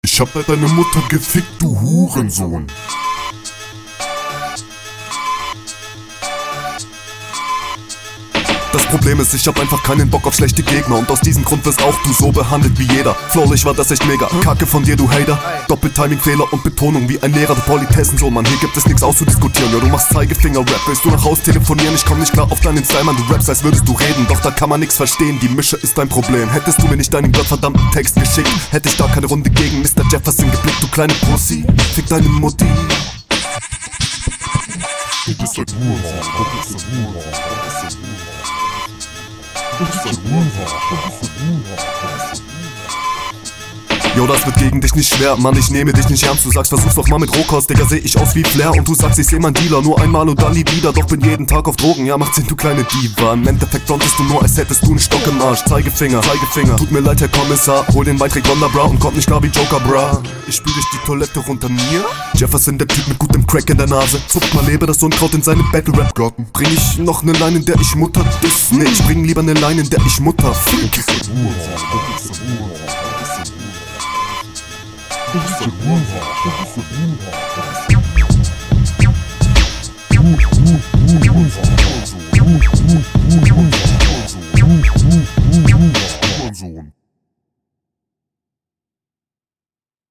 Flow: Flowlich bist du hier wiedermal etwas weiter vorne.